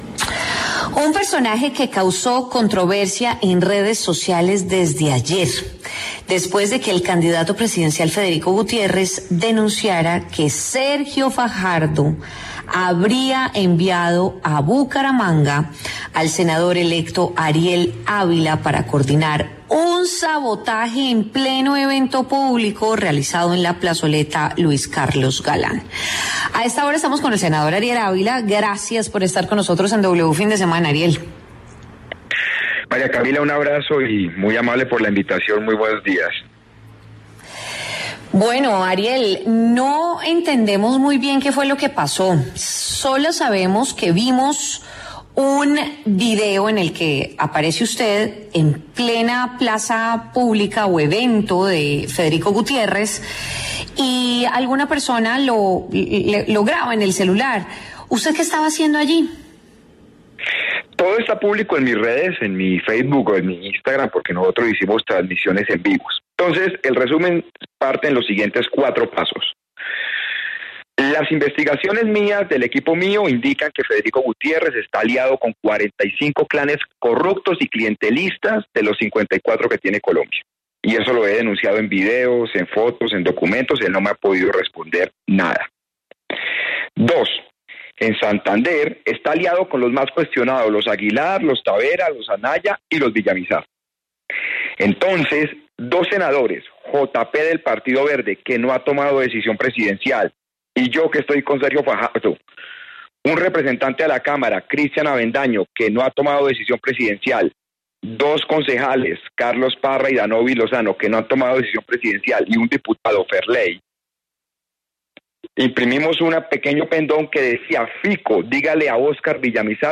Ariel Ávila, senador electo, habló en W Fin de Semana sobre la polémica por un video en el que aparece en un evento de Federico Gutiérrez.